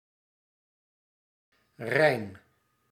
^ German: Rhein [ʁaɪn] ; French: Rhin[1] [ʁɛ̃] ; Dutch: Rijn [rɛin]
Nl-Rijn.ogg.mp3